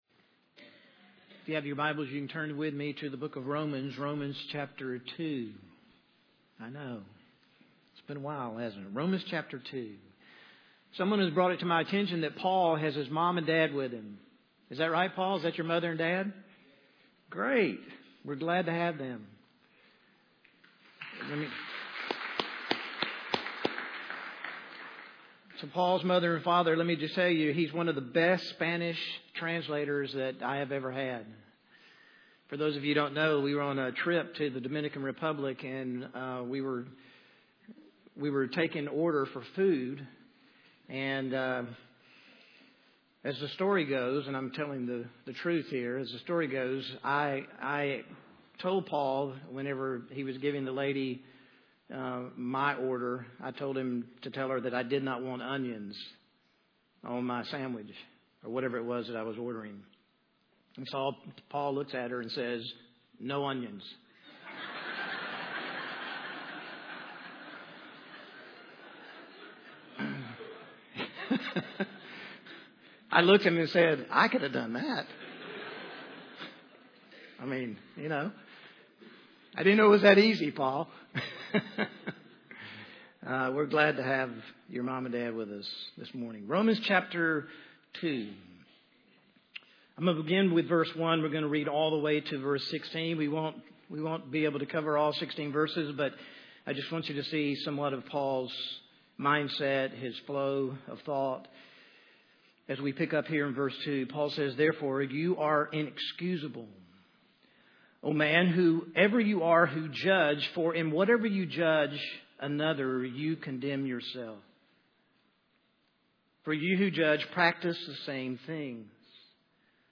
Sermons - Mt. Zion Baptist Church